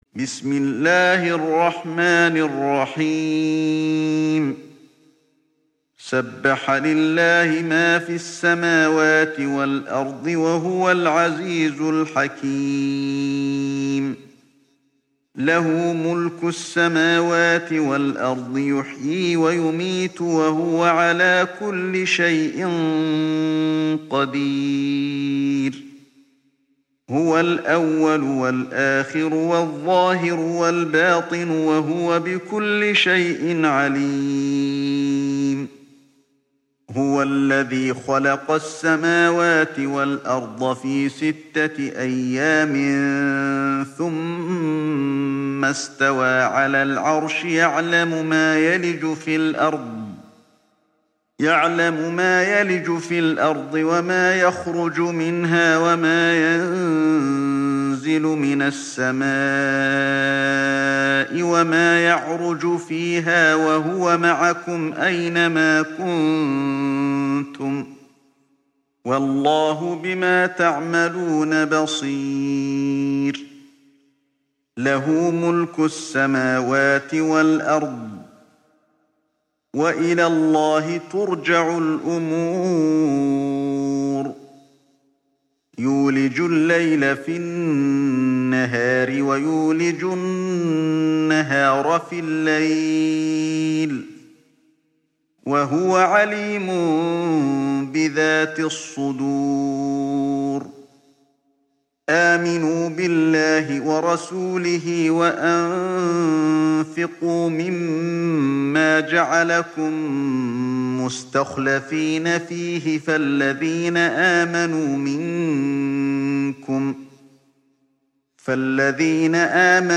تحميل سورة الحديد mp3 بصوت علي الحذيفي برواية حفص عن عاصم, تحميل استماع القرآن الكريم على الجوال mp3 كاملا بروابط مباشرة وسريعة